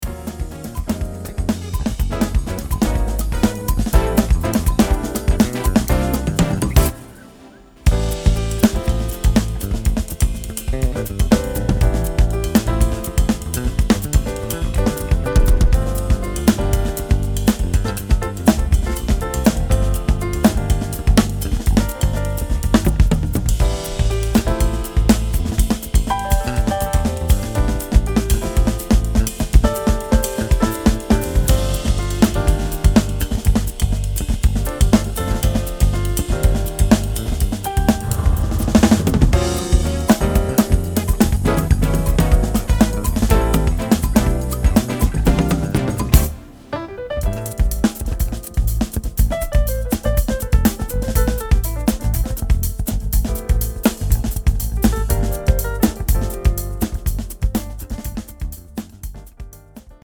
爽やかで軽やかなコンテンポラリー・フュージョン・ジャズをメロディアスに披露！
ジャンル(スタイル) NU-JAZZ / JAZZ / FUSION